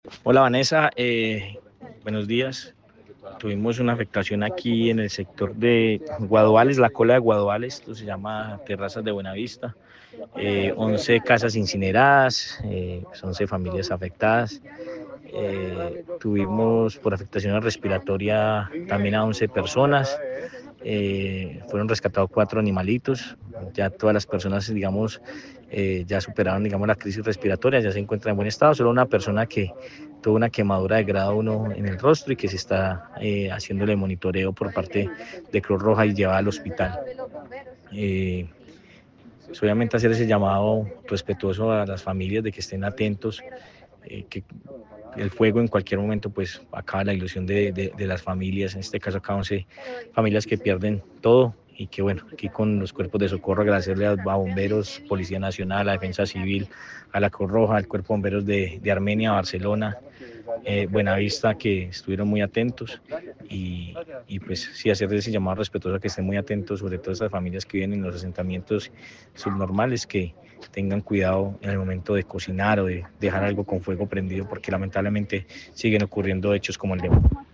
Alcalde del municipio de Calarcá sobre incendio